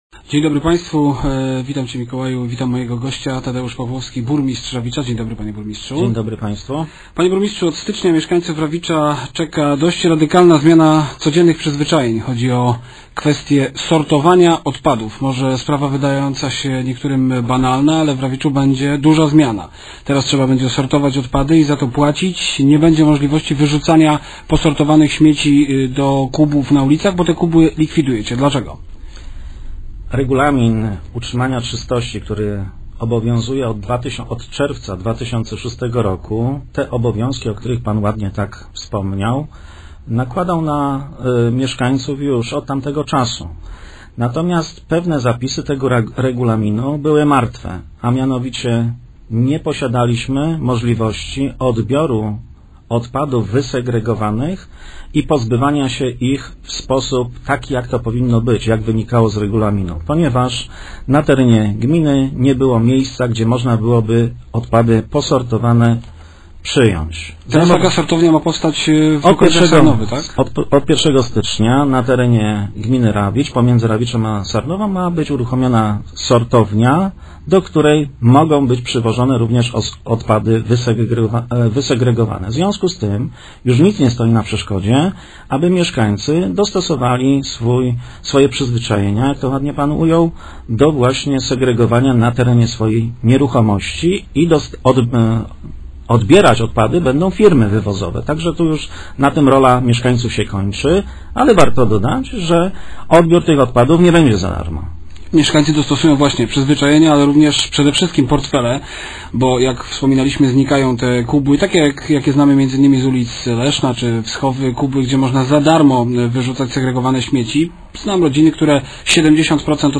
- Będziemy to ścigać– mówił dziś w Rozmowach Elki burmistrza Rawicza, Tadeusz Pawłowski.